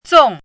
chinese-voice - 汉字语音库
zong4.mp3